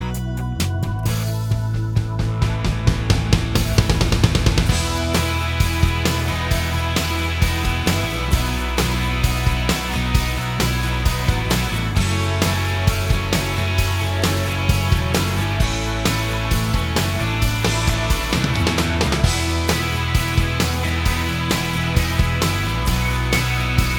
Minus All Guitars Pop (2010s) 4:42 Buy £1.50